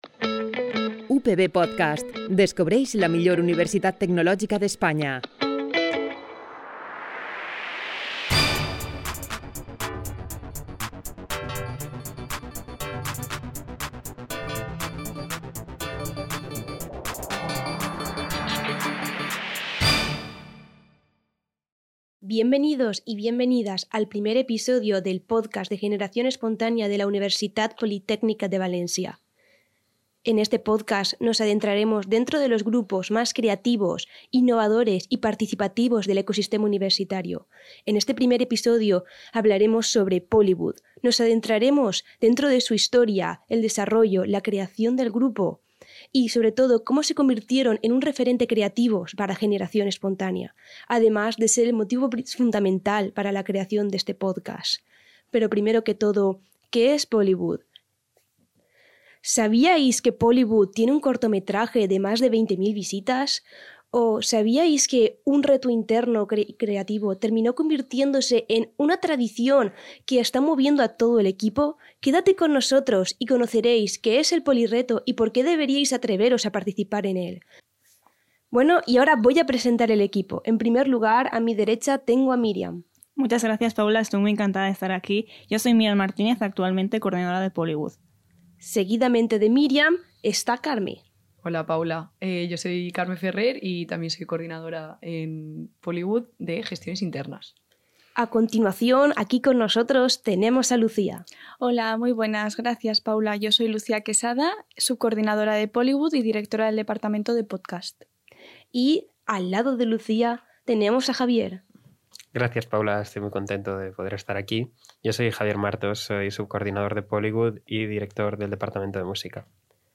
A través d’entrevistes i converses pròximes, el pòdcast revela la passió, el talent i la col·laboració que hi ha darrere d’aquestes iniciatives estudiantils.